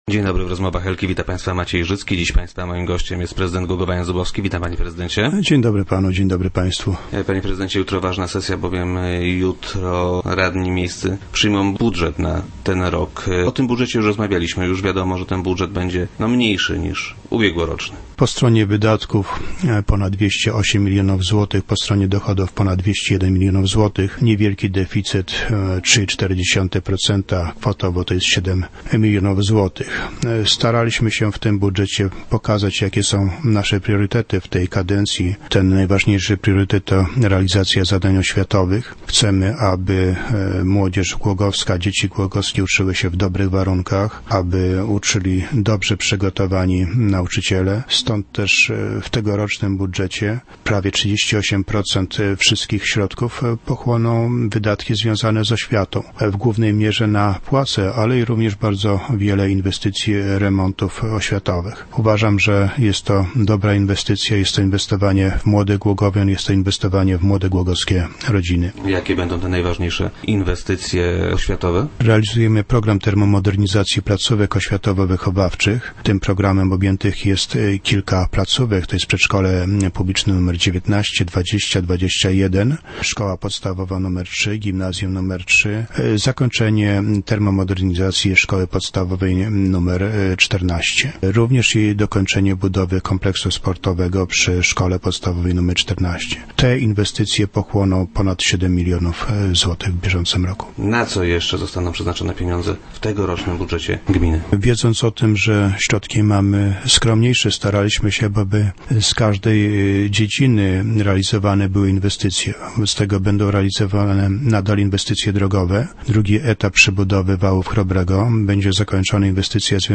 - Staraliśmy się w tym budżecie pokazać, jakie są nasze priorytety w tej kadencji. Najważniejszy z nich to realizacja zadań oświatowych. Chcemy aby głogowskie dzieci uczyły się w dobrych warunkach, by uczyli ich dobrze przygotowani nauczyciele - powiedział prezydent Jan Zubowski, który był dziś gościem Rozmów Elki.